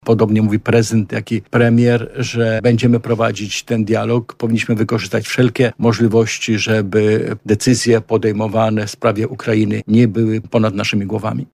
Prezydent Andrzej Duda ma się dziś (22.02) spotkać z prezydentem USA Donaldem Trumpem. Wszyscy politycy zaproszeni do studia Radia Lublin, wiązali nadzieje z tym spotkaniem.